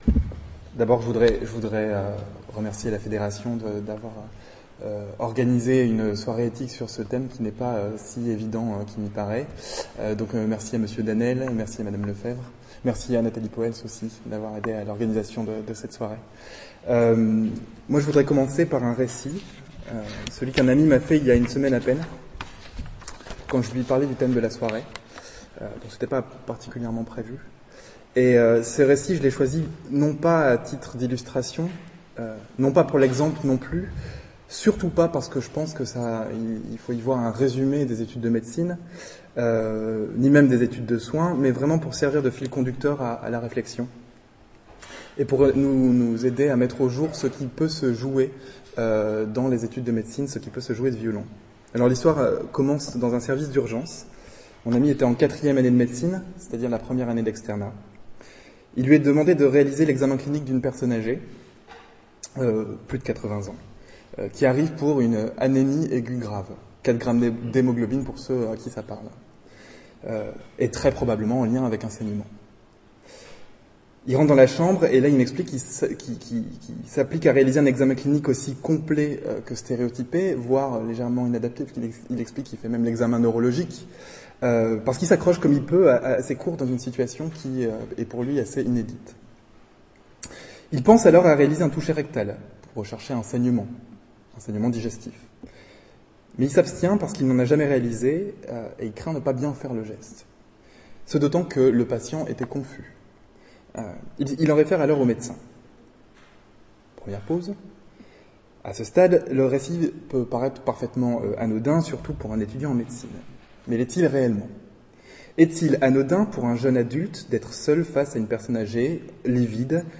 Revivez la soirée de l'espace de réflexion éthique en écoutant les interventions.